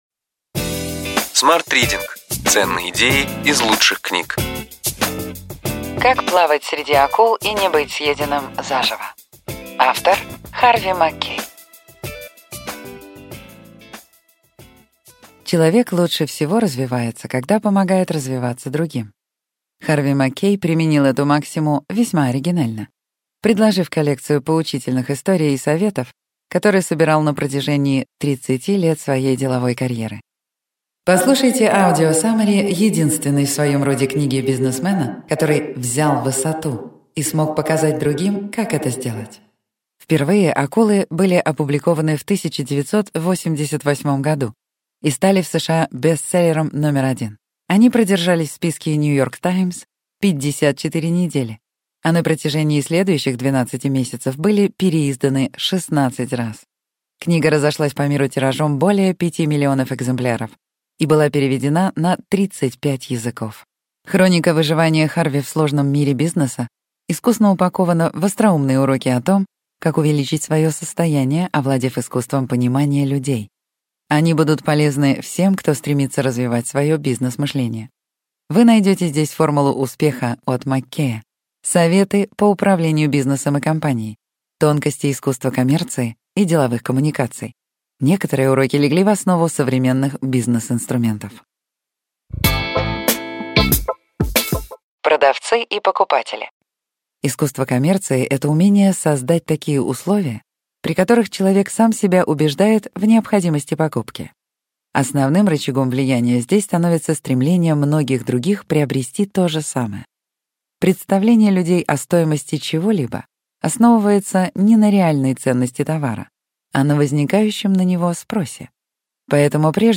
Аудиокнига Ключевые идеи книги: Как плавать среди акул и не быть съеденным заживо.